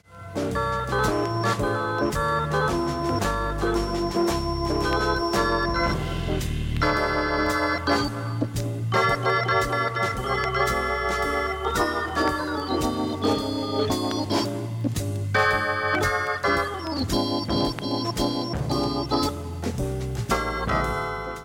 ほか３回までのかすかなプツが２箇所 単発のかすかなプツが４箇所
Stereo